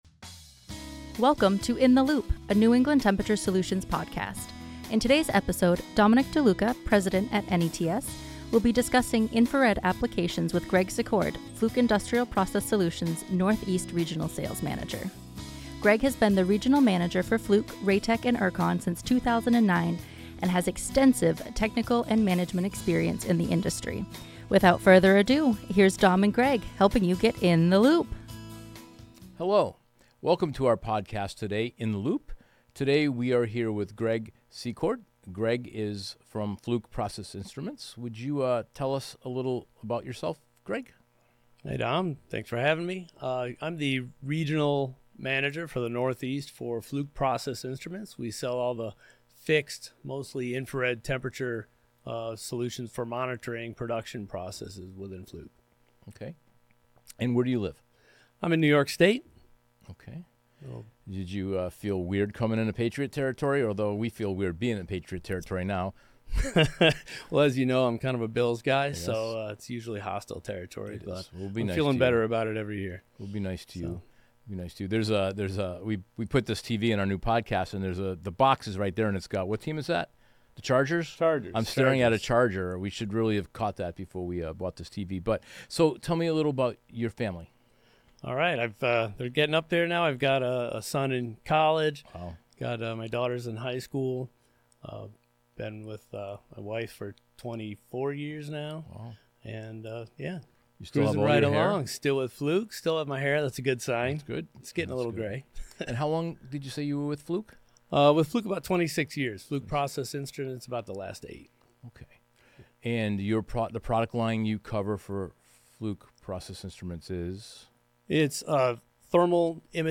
A conversation about infrared imagers